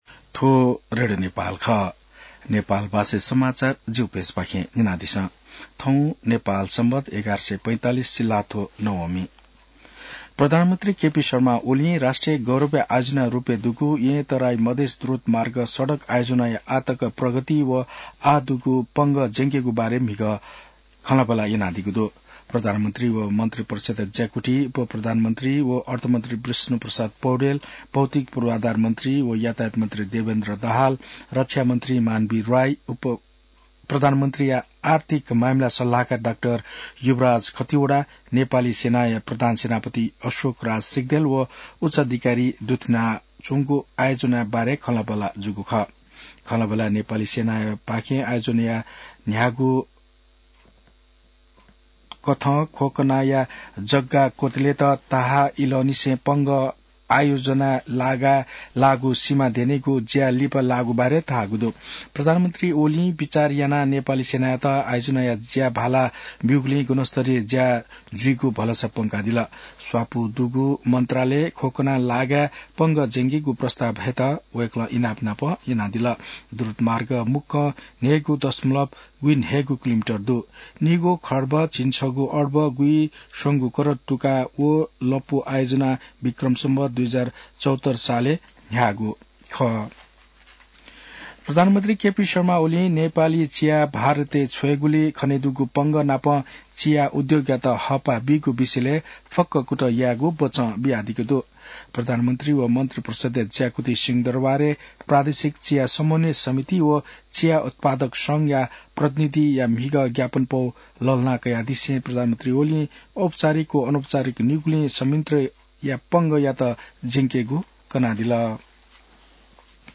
नेपाल भाषामा समाचार : २५ माघ , २०८१